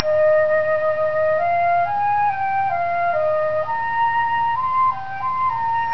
was then inverse transformed, and repacked back into a .wav file, to hear the effect on the sound, and also compressed, using a lossless compression method (UNIX compress).
flute.13q.wav